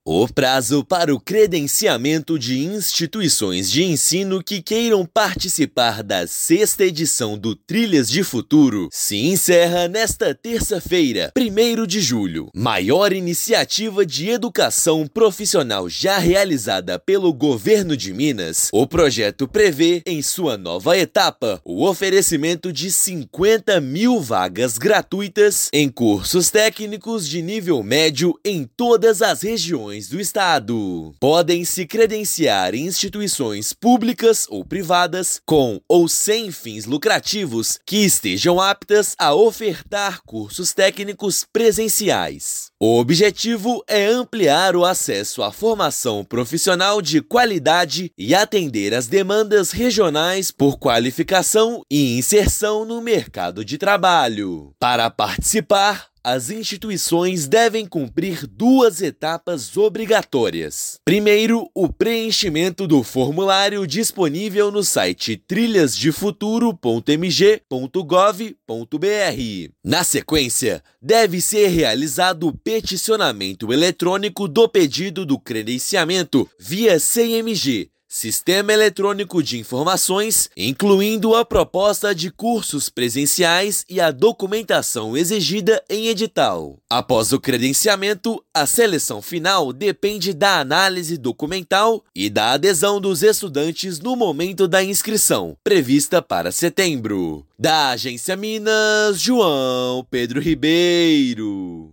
Instituições de ensino profissional interessadas devem concluir as duas etapas do processo para ofertar cursos técnicos gratuitos na 6ª edição do projeto. Ouça matéria de rádio.